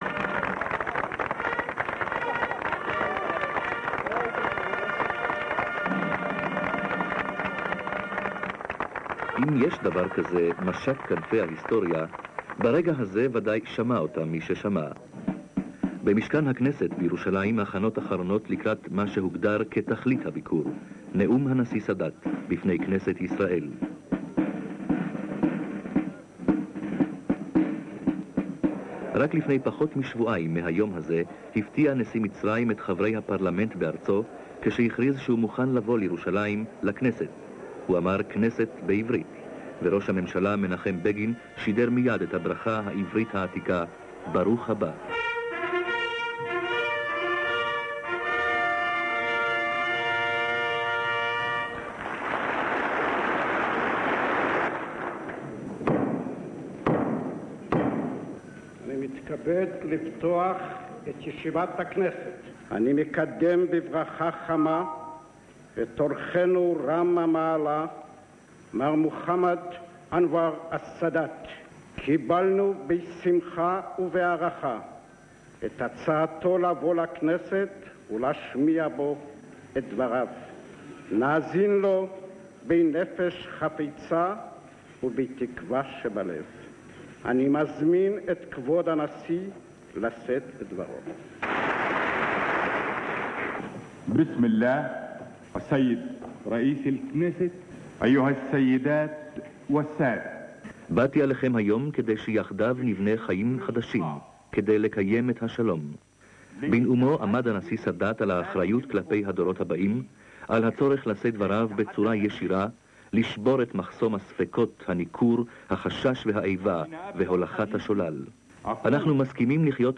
Sadat visit to Israel and Knesset with speeches of: Sadat, Begin, Peres & Golda Meir (Hebrew)
Come back in time with us to the historic visit of Egyptian President Sadat in Israel, 40 years ago... Hear the voices of Menachem Begin, Anwar Sadat, Shimon Peres and Golda Meir...